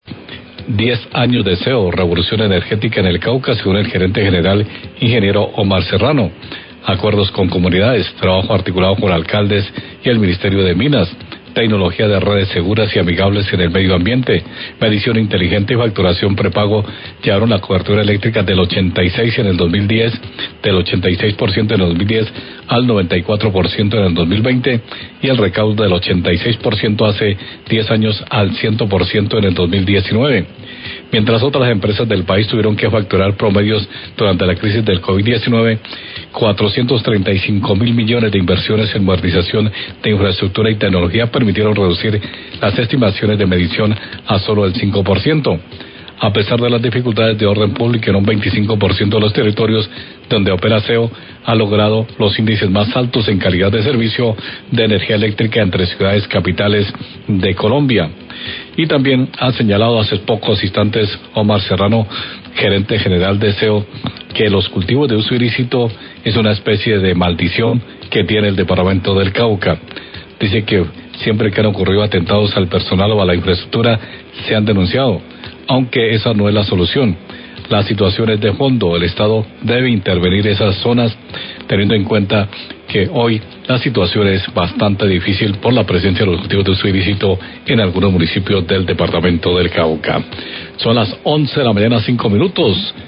Radio
columna de opinión